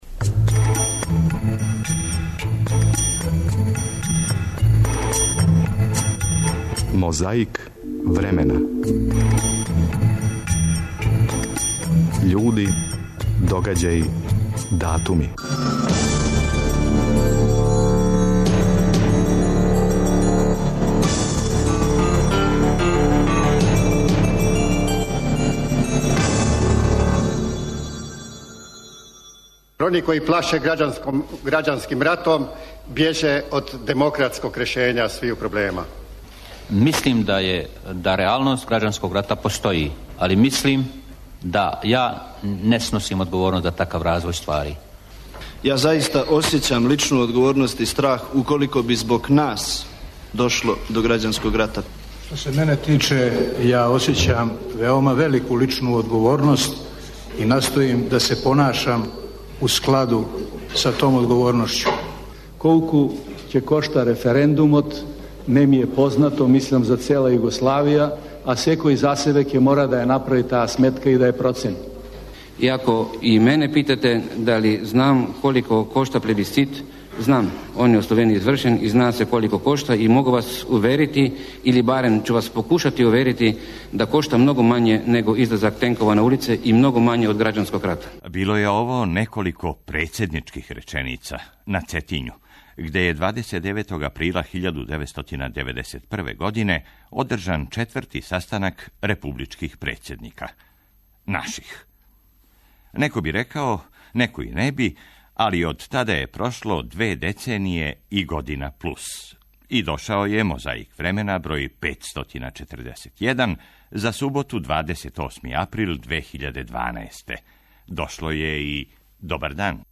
Чућете како су се учесници неких ранијих прослава овог празника сећали пред микрофоном Радио Београда 29. априла 1961.
Уз снимке из Тонског архива Радио Београда подсећамо.